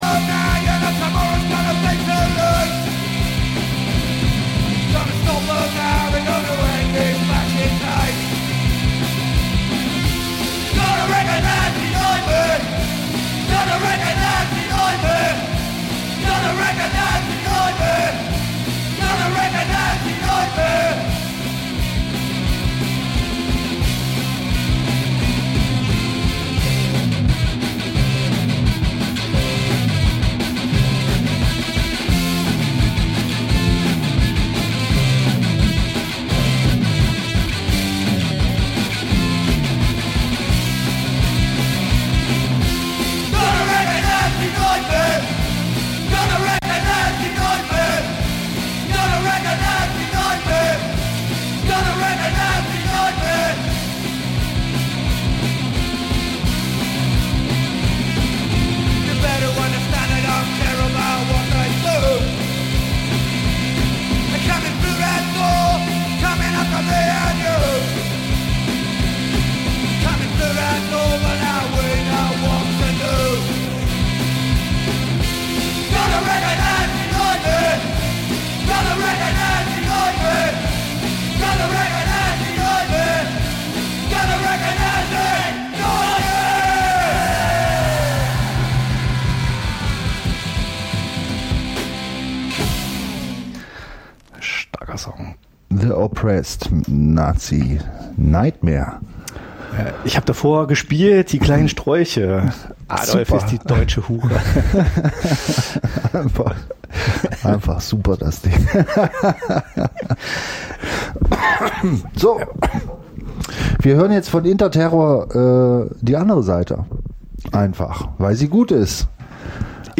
Den geneigten H�rer erwartet ein langsam erhitzter fein abgeschmeckter musikalischer Eintopf aus erlesenen internationalen Zutaten. ROCKABILLY der alten schule, jamaikanischer SKA, wilder RHYTHM�N�BLUES, zuckers��er ROCKSTEADY, hei�er ROCK�N�ROLL, karibischer CALYPSO, stampfender NORTHERN SOUL, early REGGAE und leicht gesalzenes POPCORN bilden das Grundrezept. Je nach Laune und dem jeweiligen Schallplattenunterhalter(n) wird eventuell mit Psychobilly, Punkrock, 2Tone, DooWop, Blues sowie Jazz verfeinert oder auch nicht, solang es den Ohren schmeckt.